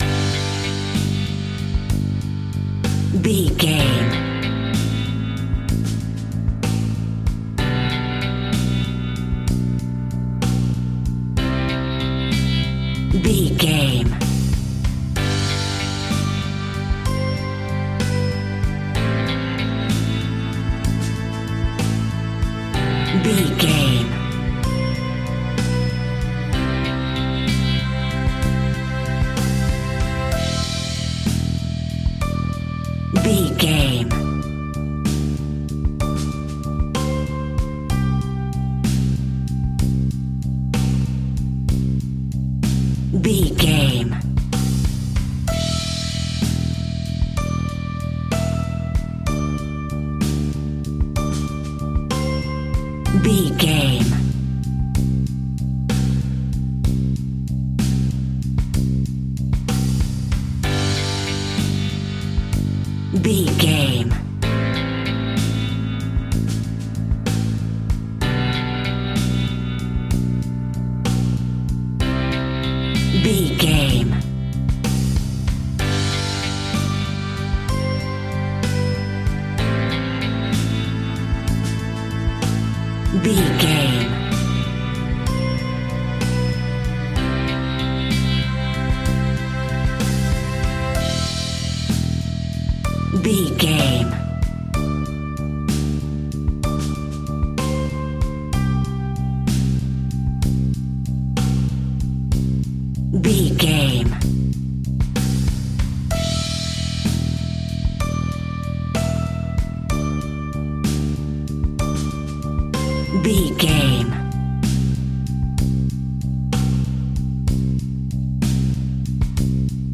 Ionian/Major
Slow
indie pop
indie rock
pop rock
sunshine pop music
drums
bass guitar
electric guitar
piano
hammond organ